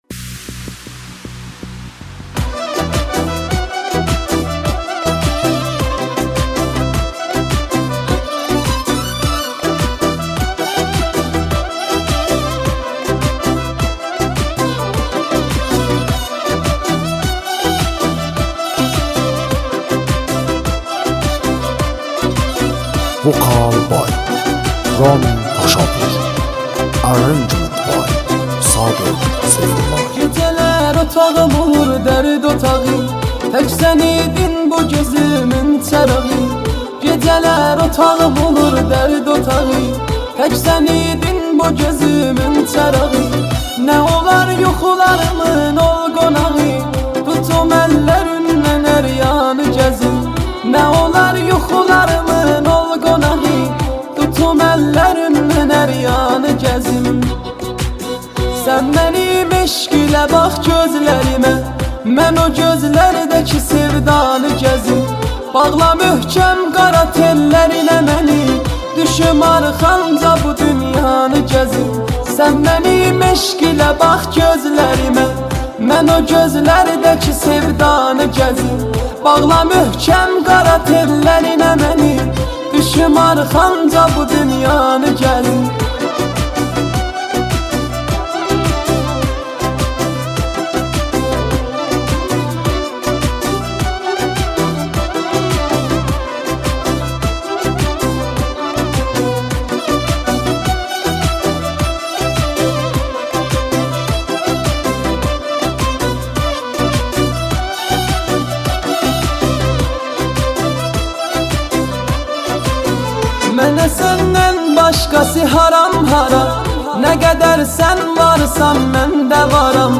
آهنگ ترکی غمگین معروف در اینستاگرام